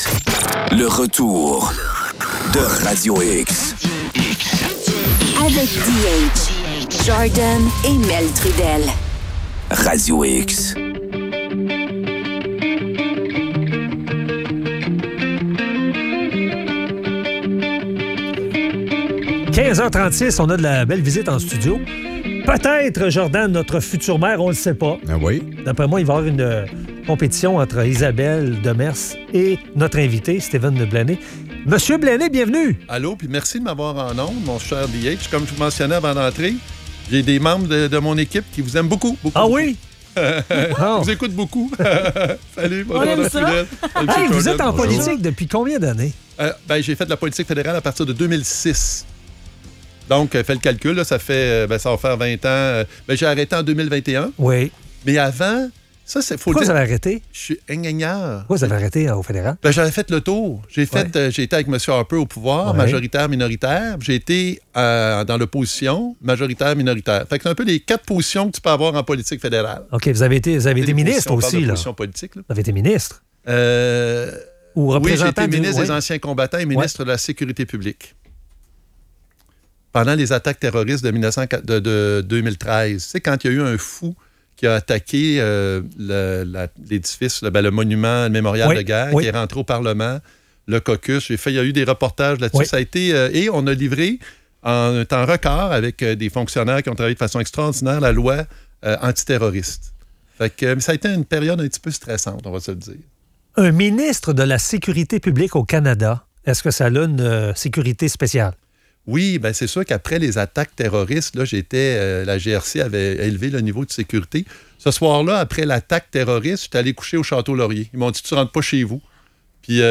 Entrevue avec Steven Blaney, candidat à la mairie de Lévis.